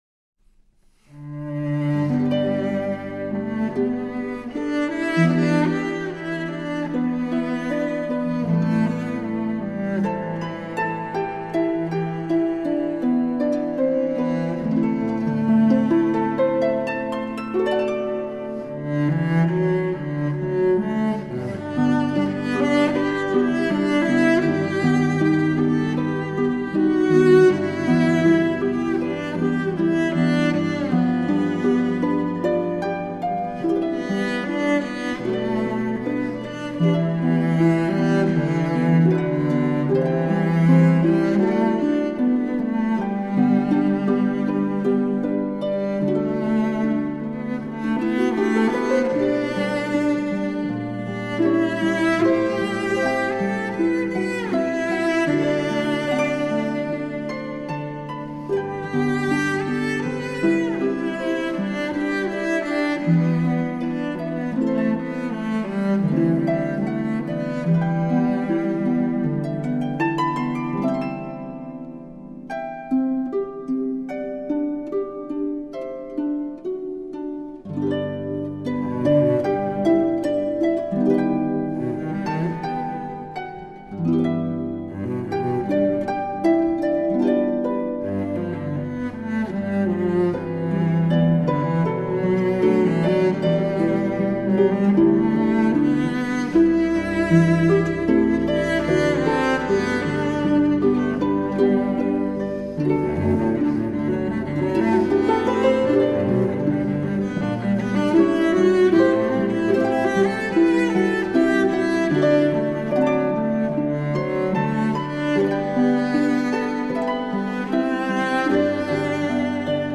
大提琴、竖琴